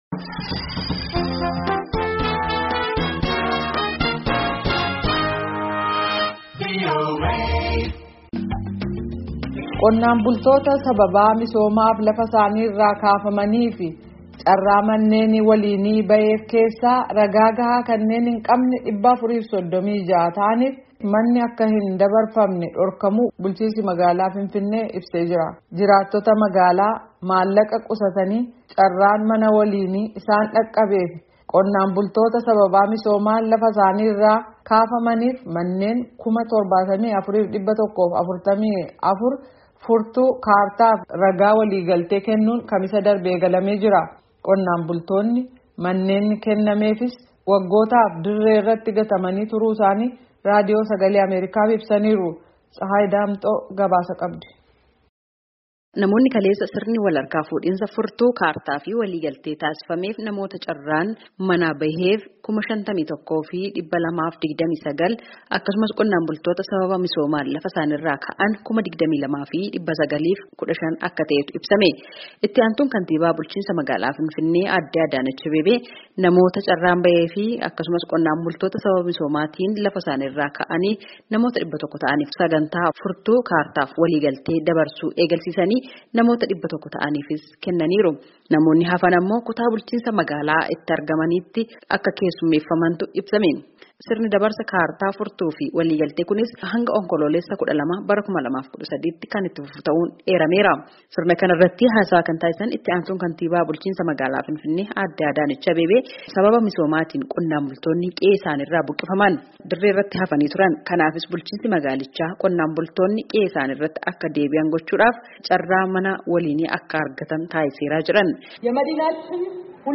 Qonnaan bultoonni manni kennameefis waggootaaf dirree irratti gatamanii turuu isaanii Raadiyoo Sagalee Ameerikaaf ibsan.